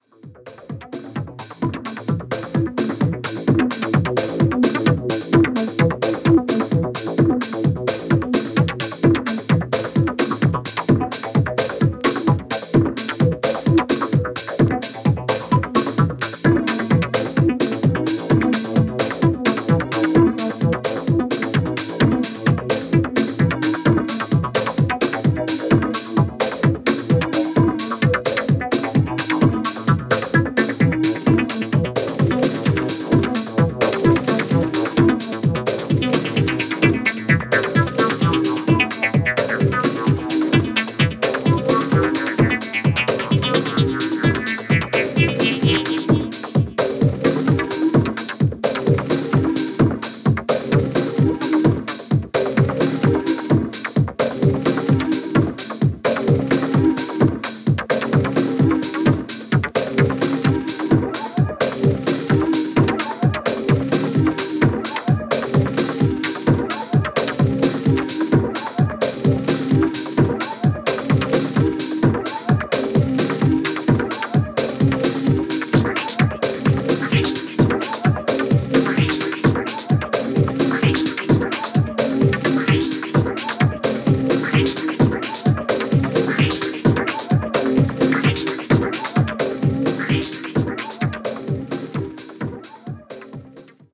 Keyboards
Drums